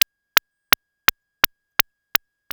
ihob/Assets/Extensions/RetroGamesSoundFX/UFO/UFO01.wav at master
UFO01.wav